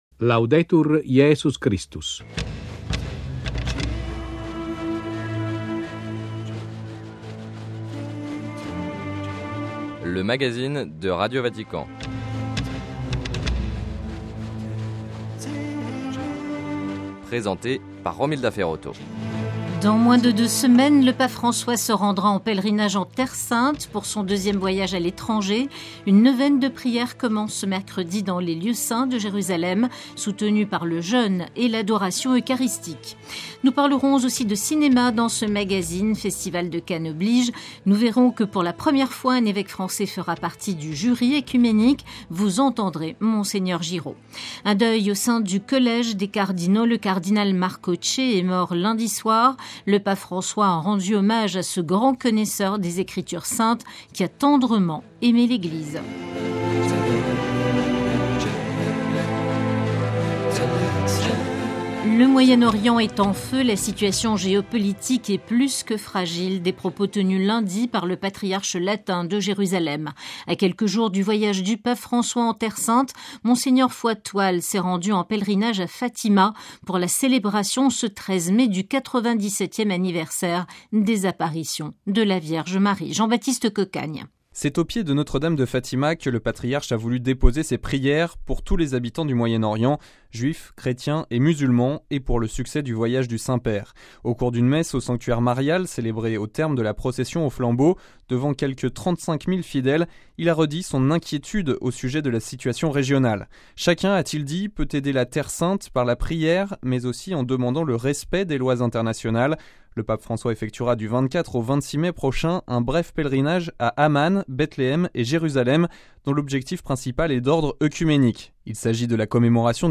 - La Jordanie se prépare à accueillir le Saint-Père : entretien avec le cardinal Jean-Louis Tauran, président du Conseil pontifical pour le dialogue interreligieux, en visite à Amman.
- Pour la première fois, un évêque français fait partie du jury œcuménique au festival de Cannes : entretien avec Mgr Hervé Giraud, évêque de Soissons.